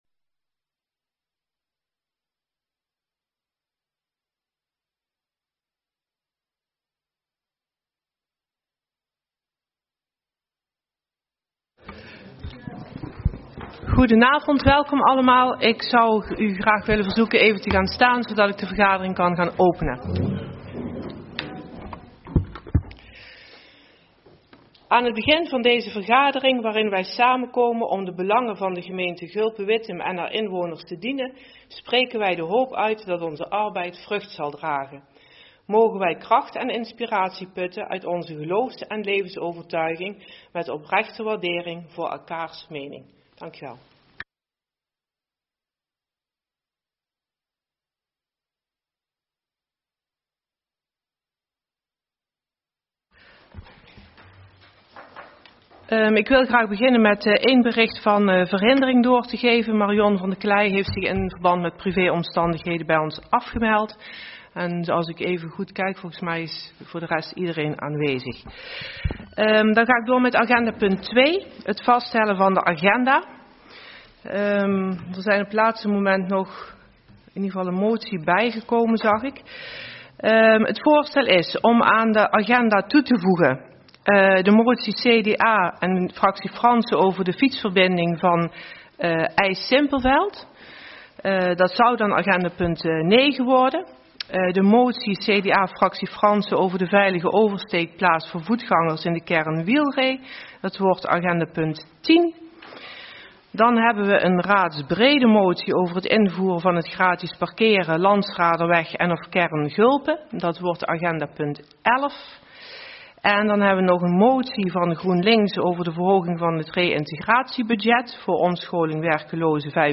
Agenda GulpenWittem - Raadsvergadering donderdag 25 januari 2018 19:30 - 22:30 - iBabs Publieksportaal
Locatie Raadzaal